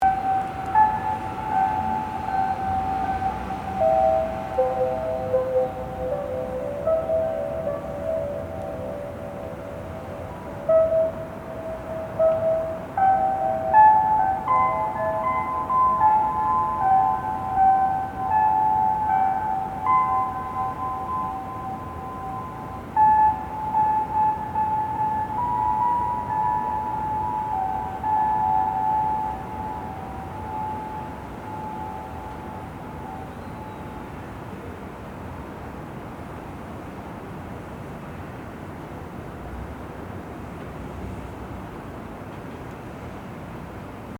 I want to record the sound of the bells at 5pm.